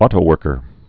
tō-wŭrkər)